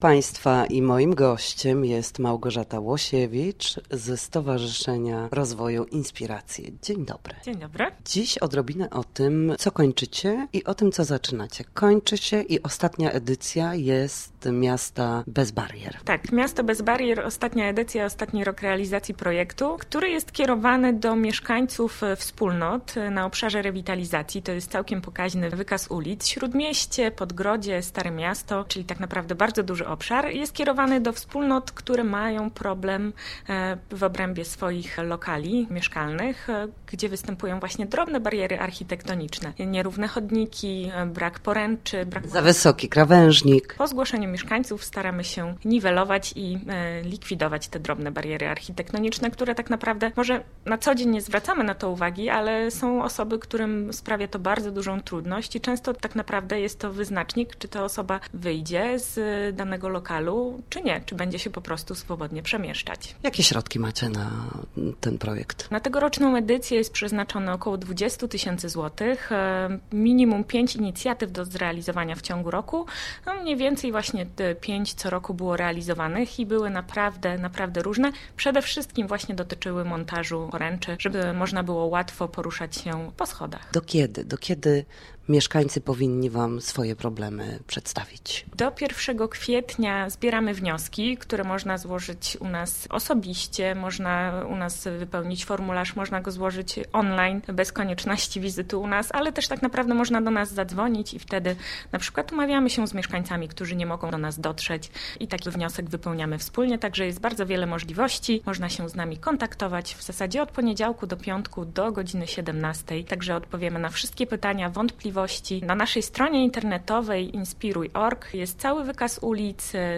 Zgłoszenia do programu „Miasto bez barier” trwają [POSŁUCHAJ ROZMOWY]